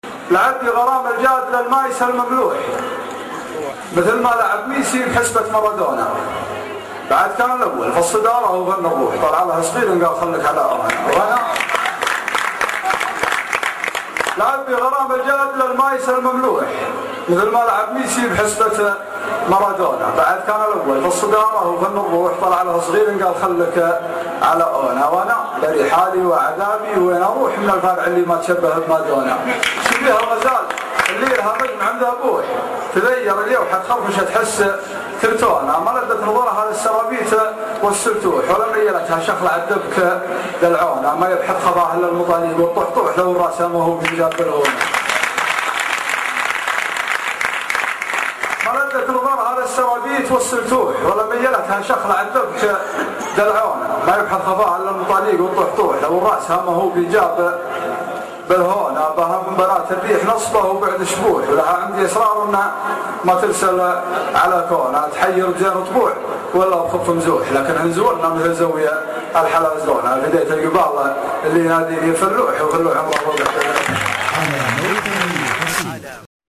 لعب بي غرام الجادل ( اصبوحة جامعة الكويت